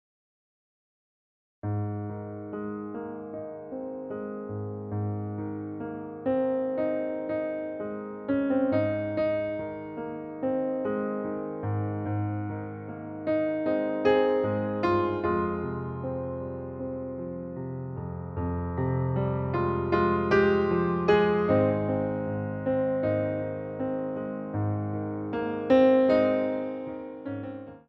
PLIÉS - 4/4